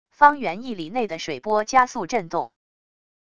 方圆一里内的水波加速震动wav音频